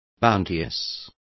Complete with pronunciation of the translation of bounteous.